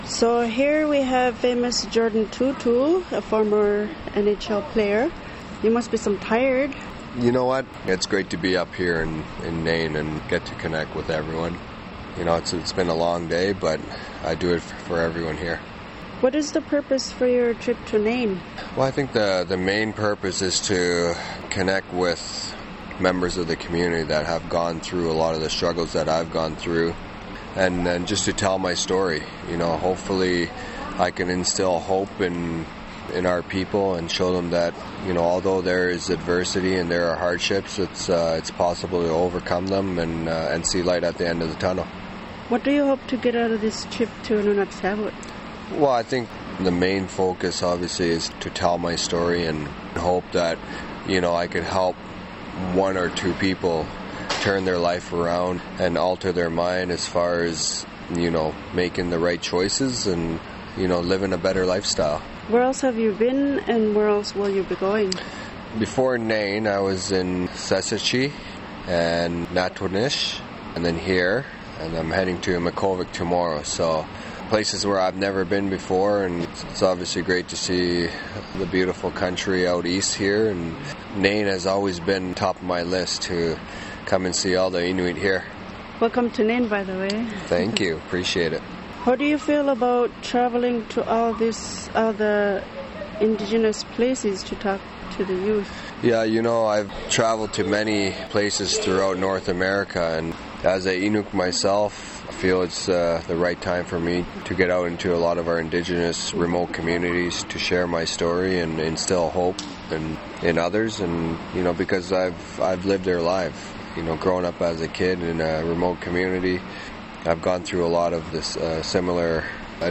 We had one of our radio producers attend this event at the Jeremias Sillitt Community Centre and did an interview with Jordin Tootoo.